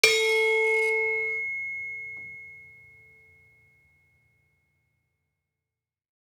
Gamelan Sound Bank
Saron-2-A3-f.wav